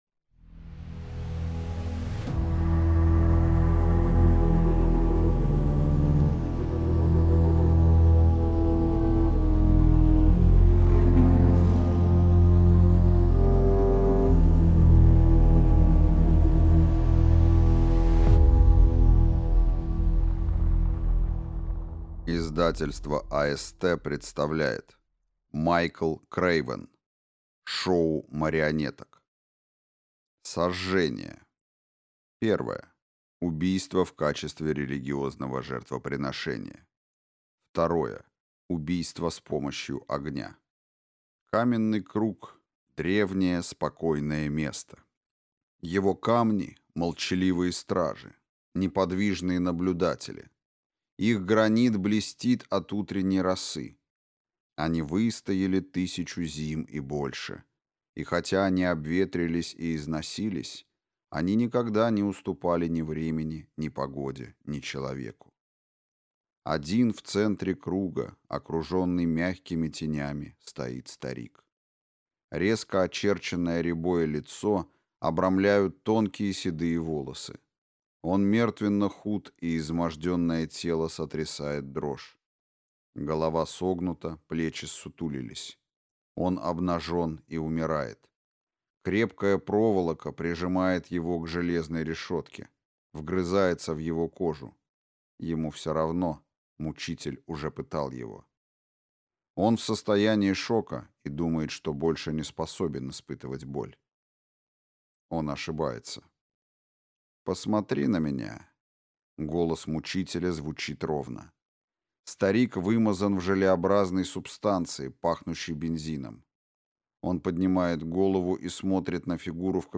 Аудиокнига Шоу марионеток | Библиотека аудиокниг
Прослушать и бесплатно скачать фрагмент аудиокниги